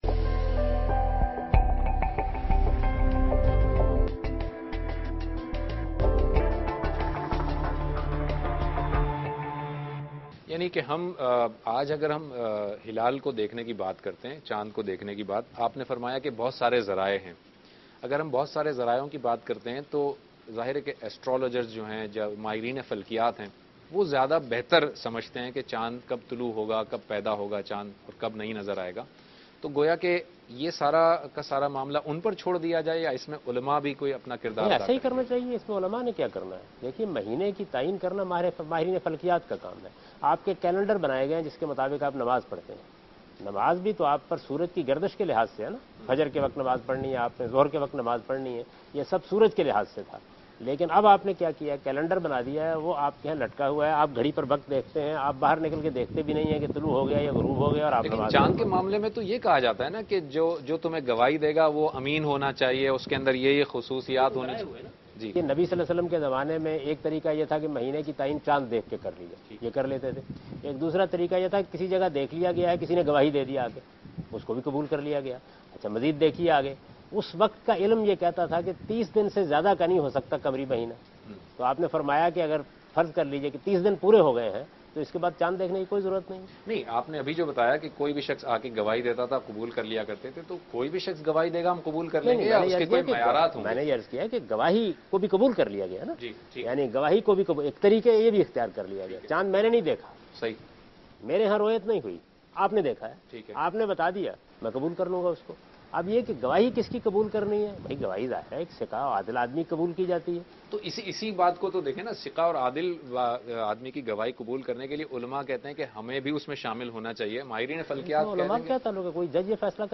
دنیا ٹی وی کے پروگرام دین ودانش میں جاوید احمد غامدی عید اور ہماری ذمہ داریوں کے متعلق گفتگو کر رہے ہیں